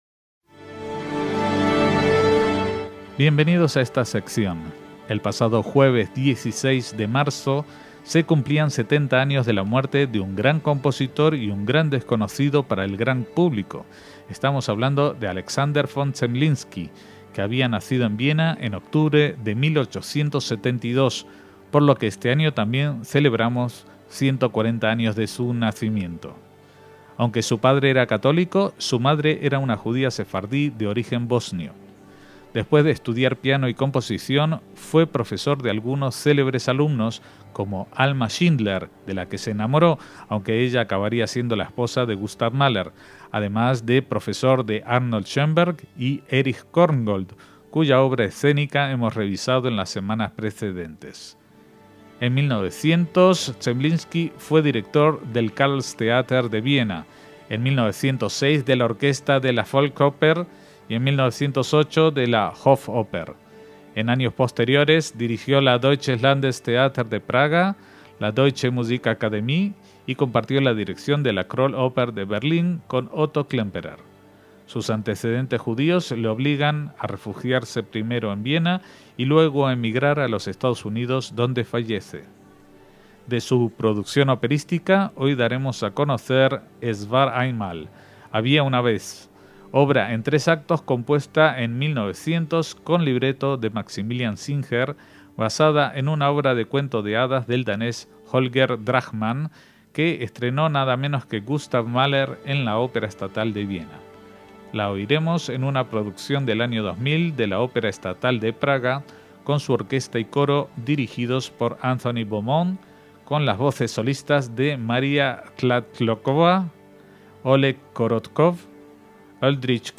ópera de cuento de hadas en un prólogo y tres actos
grabación del año 2000
la orquesta y coro
entre otras voces solistas